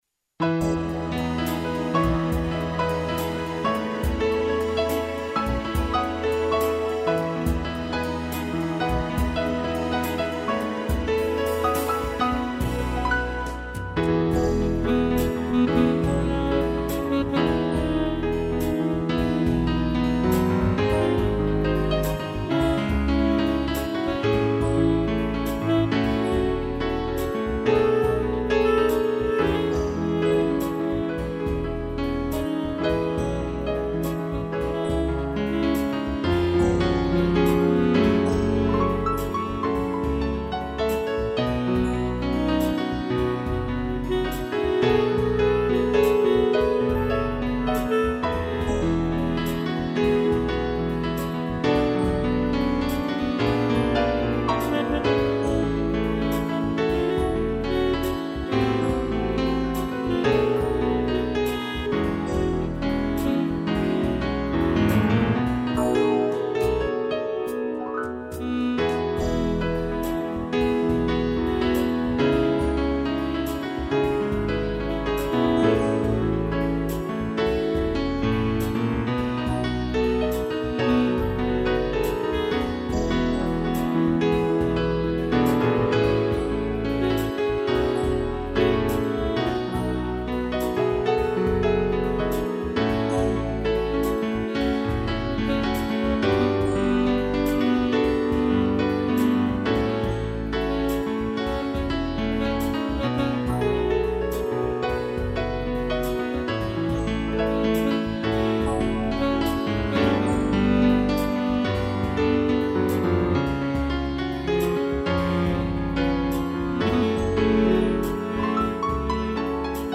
piano
instrumental